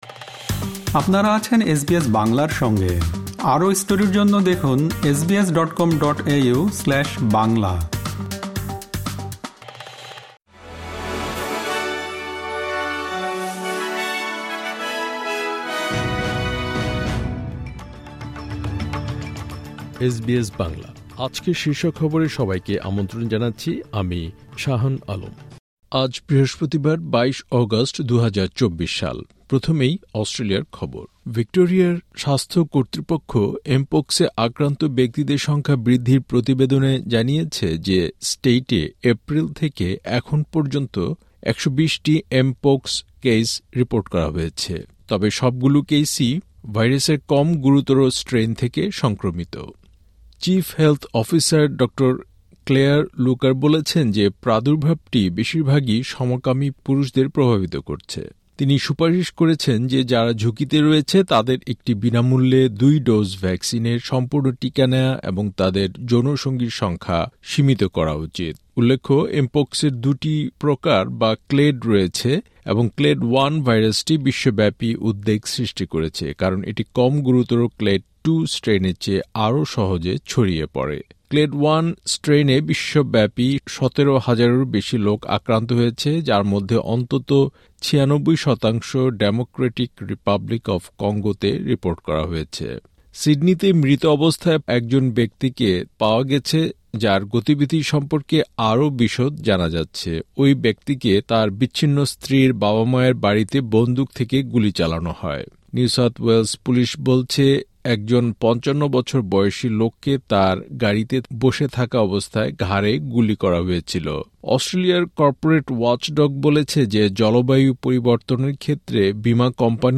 এসবিএস বাংলা শীর্ষ খবর: ২২ অগাস্ট, ২০২৪